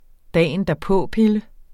Udtale [ ˈdæˀəndɑˈpɔˀˌpelə ]